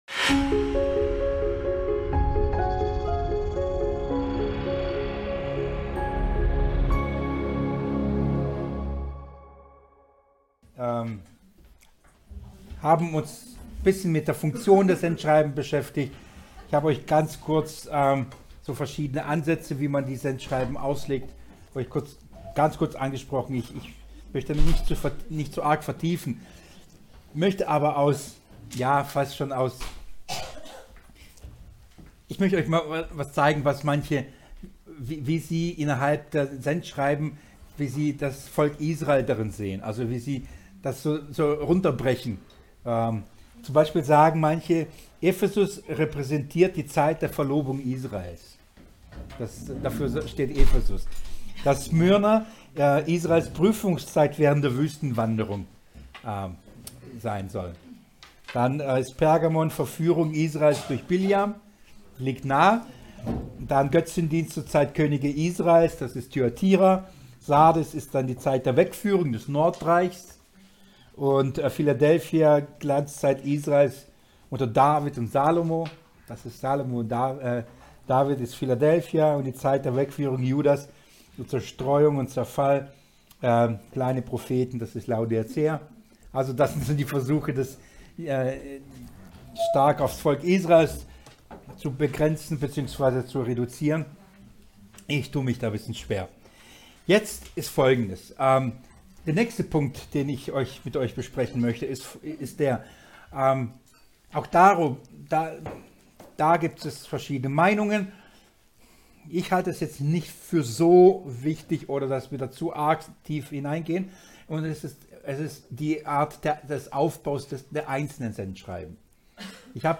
Die Einheit am Kreuzlicht-Seminar fand am 16.02.2019 in Heubach statt.